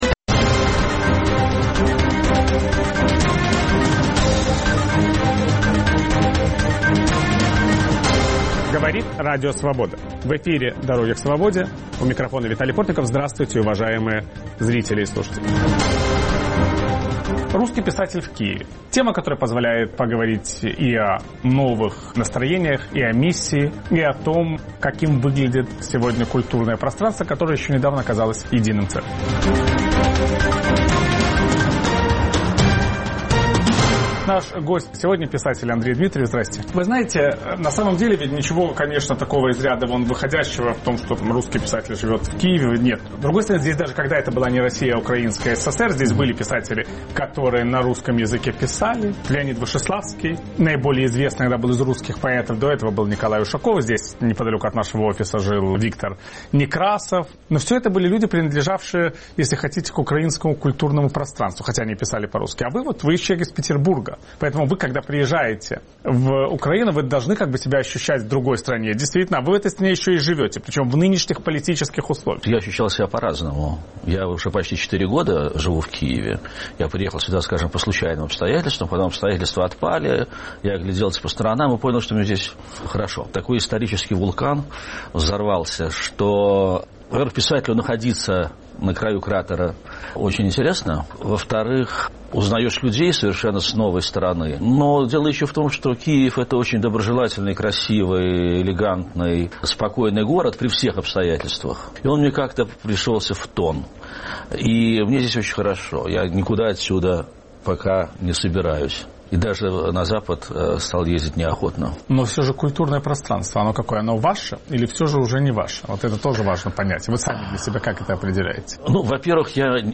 Виталий Портников беседует с писателем Андреем Дмитриевым